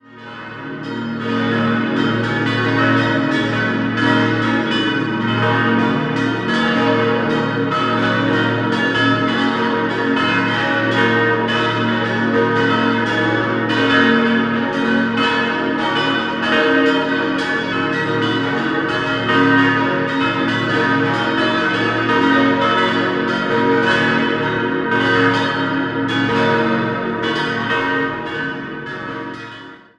Die Erlöserkirche steht im Ortsteil Rheindorf. Geplant von Willibald Braun und Emanuel Thurnherr wurde sie 1933/34 als basilikale Anlage erbaut. 6-stimmiges Geläut: b°-des'-es'-ges'-as'-b' Die Glocken wurden bei der Gießerei Grassmayr in Innsbruck gefertigt: die große 2001, alle anderen 1949.